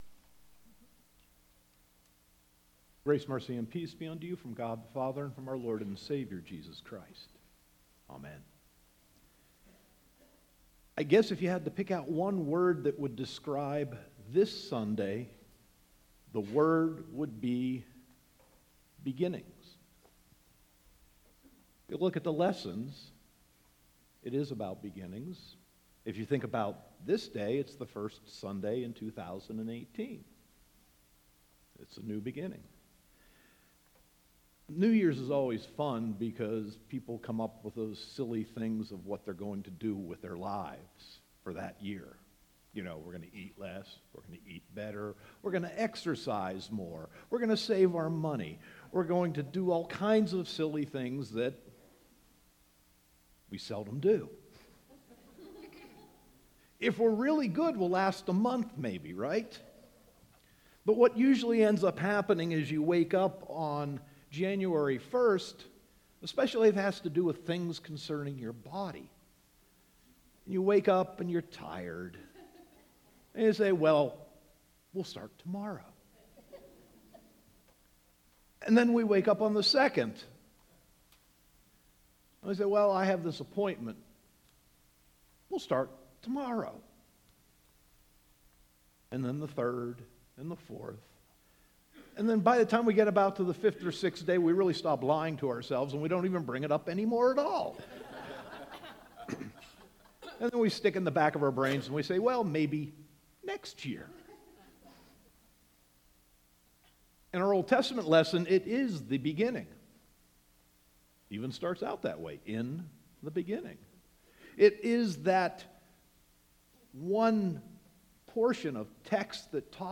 Sermon 1.7.2018